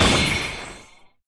音效
Media:R_Magical_Shelly_atk_001_REV2.wav 攻击音效 atk 局内攻击音效